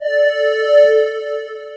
Mid Space Flute.wav